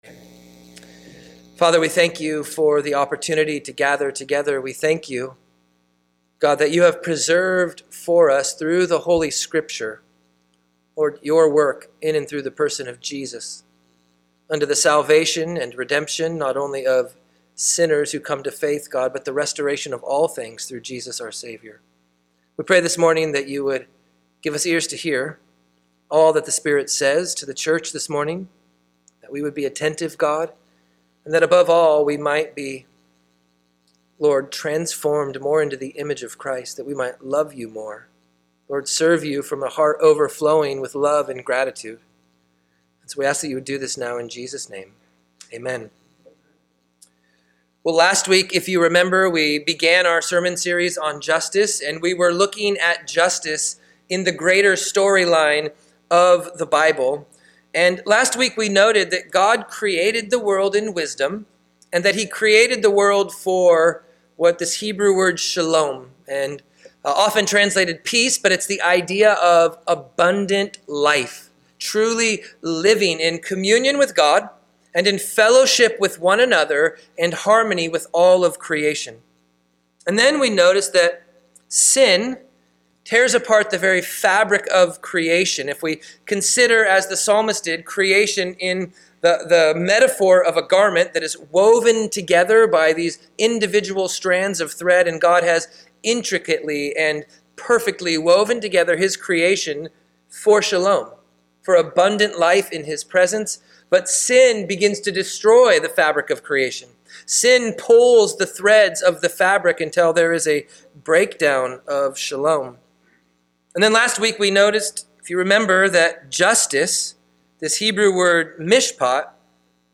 This is our second sermon in our series on biblical justice.